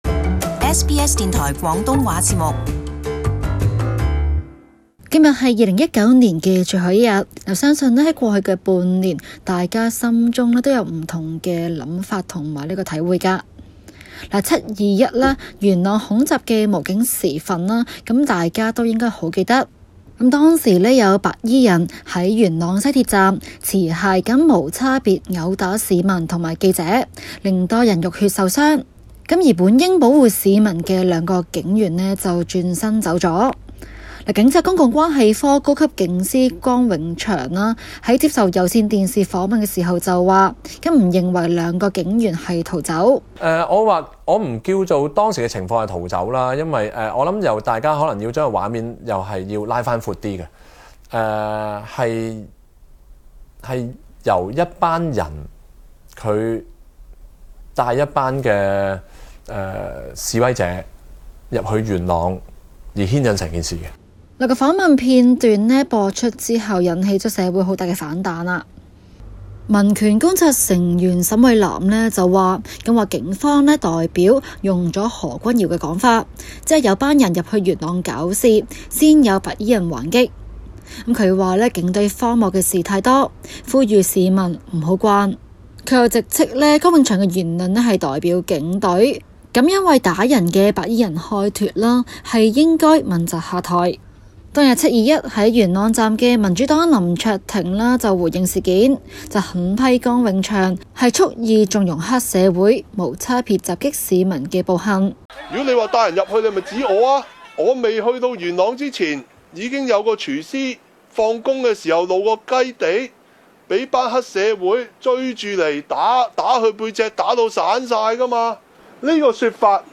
中港快訊